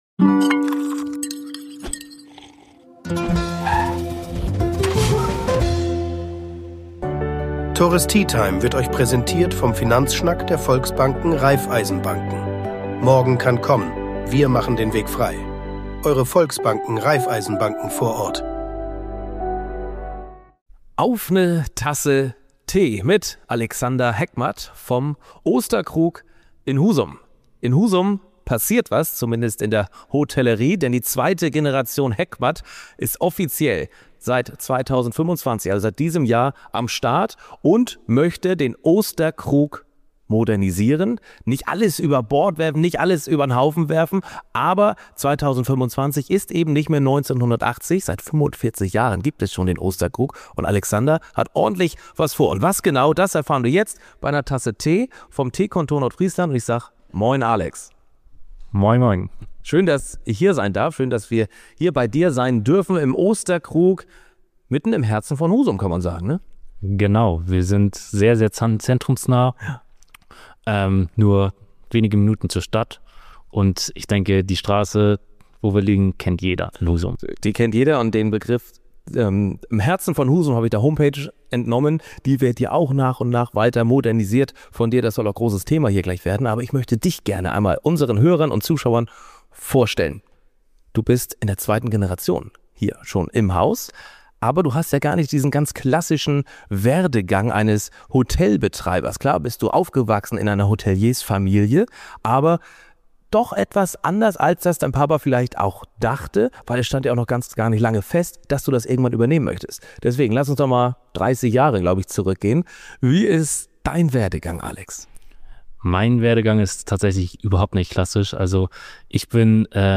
Und wie verändert sich das Gastgewerbe in Zeiten von Personalmangel, Digitalisierung und neuen Gästebedürfnissen? Ein ehrliches Gespräch mit einem jungen Hotelier, der antritt, um Tradition zu bewahren und gleichzeitig Neues zu wagen.